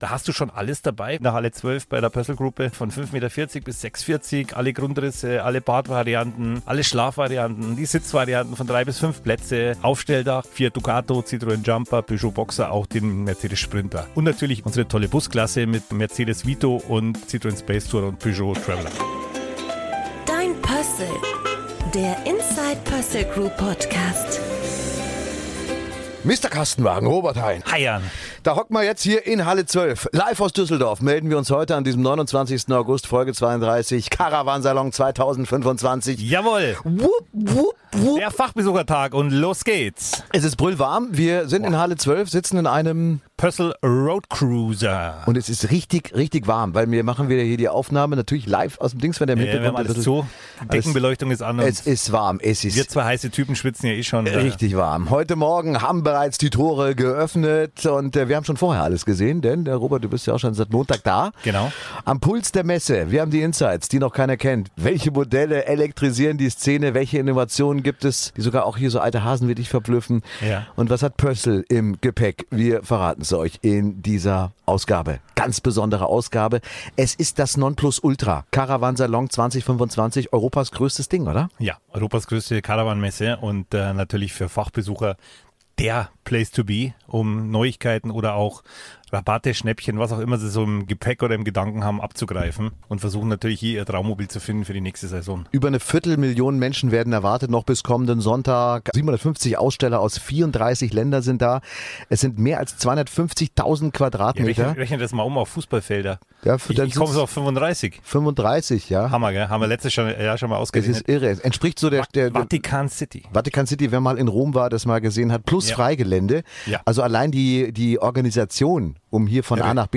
Beschreibung vor 7 Monaten Noch bevor sich die Tore öffnen – wir haben schon alles gesehen und melden uns live vom Caravan Salon 2025 in Düsseldorf.